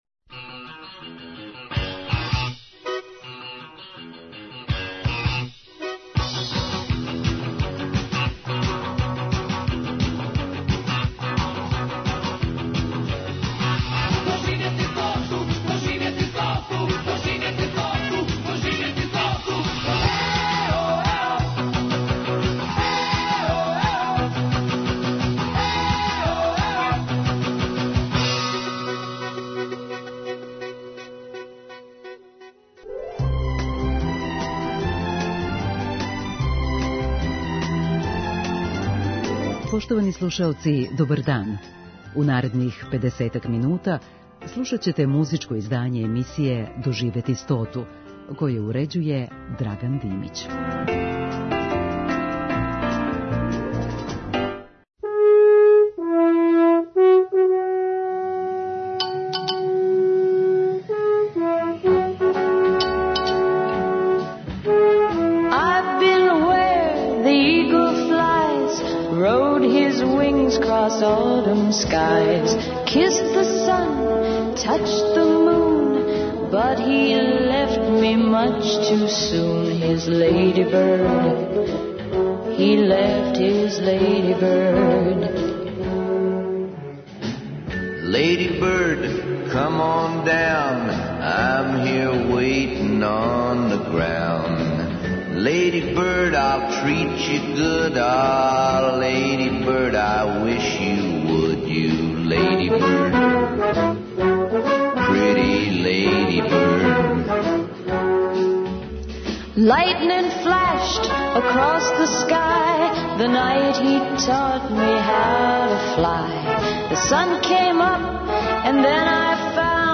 са одабраним евергрин песмама